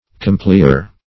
Search Result for " complier" : The Collaborative International Dictionary of English v.0.48: Complier \Com*pli"er\, n. One who complies, yields, or obeys; one of an easy, yielding temper.
complier.mp3